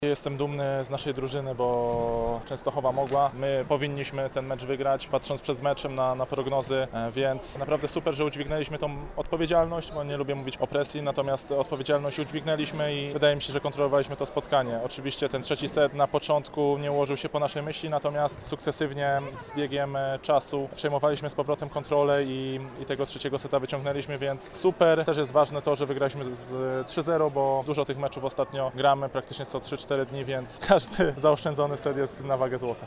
Komentarzy po meczu udzielili